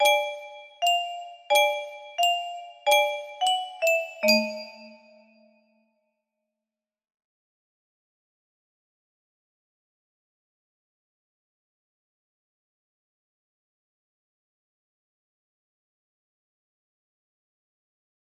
melody music box melody